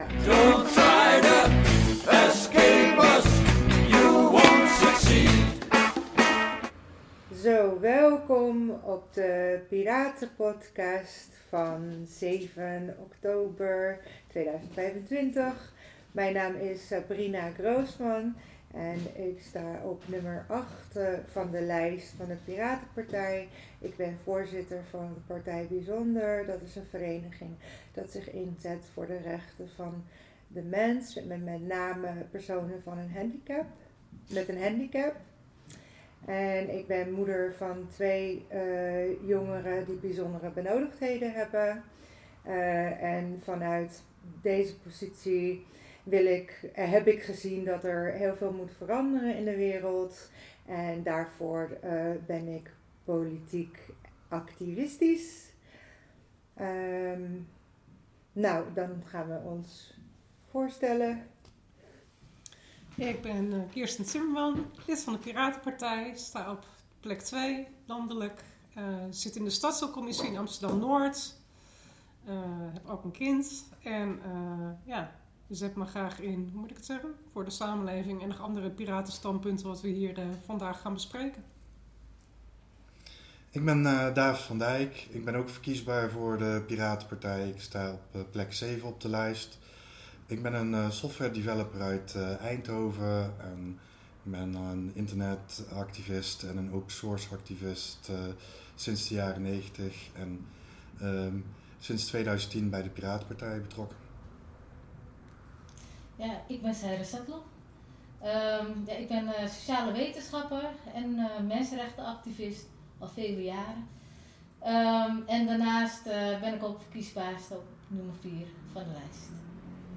Piraten Podcast 2 (7 okt 2025) Deze Piraten Podcast werd opgenomen in het Blauwe Pand, Zaandam.